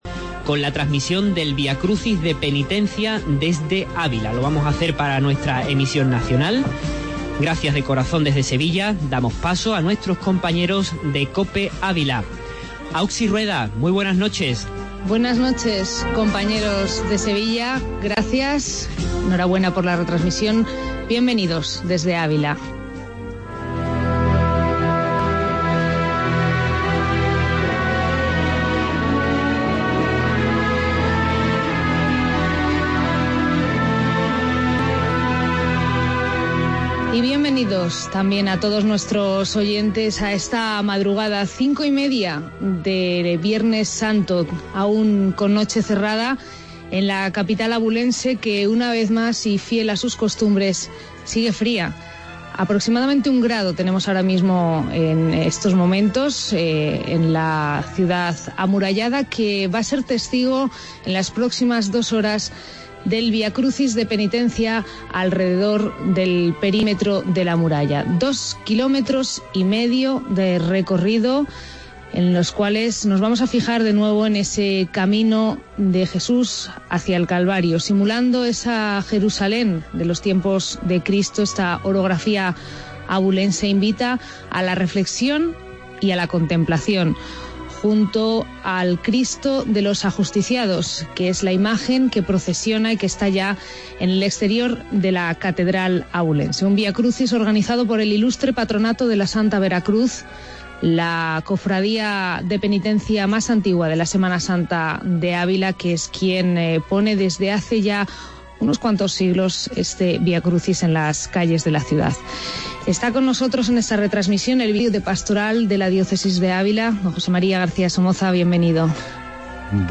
AUDIO: Vía Crucis de Penintecia de Ávila